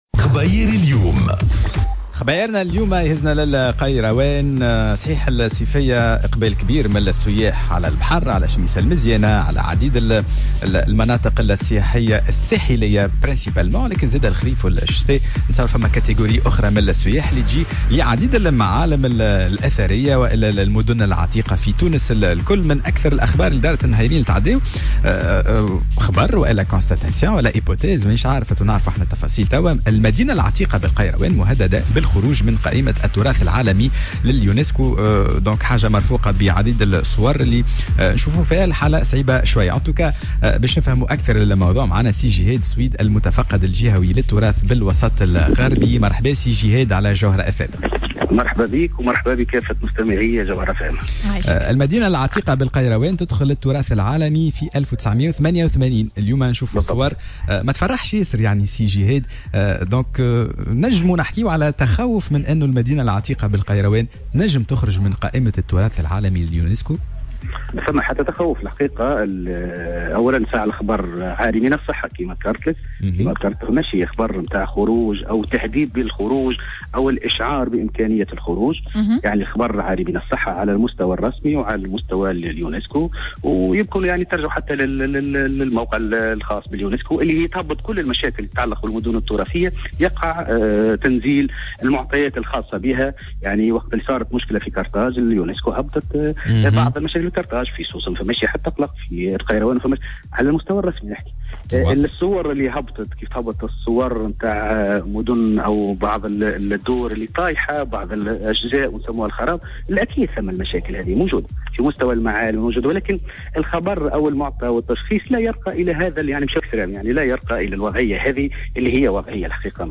في مُداخلة له على موجات الجوهرة أف أم